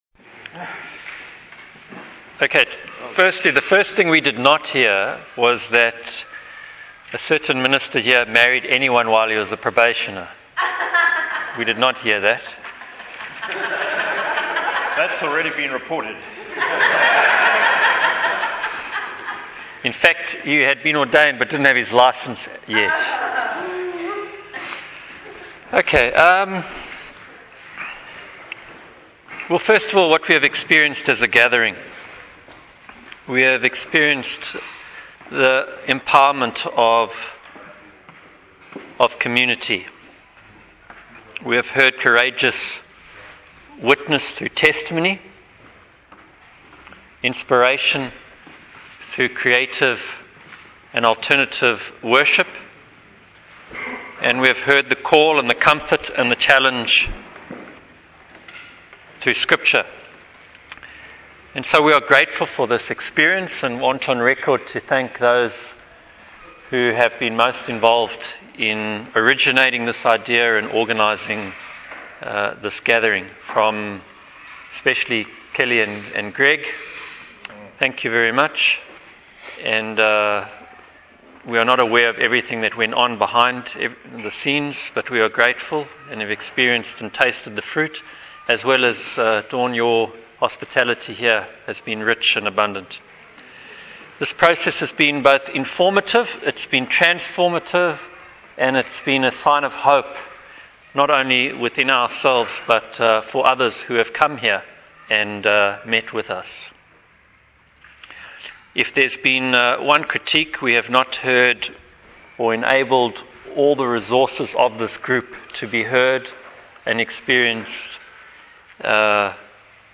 I recorded a number of these testimonies, devotions, and Bible studies. We have decided to make the report of the listening committee available (this report sums up the feelings, ideas, and experiences of the members of the meeting).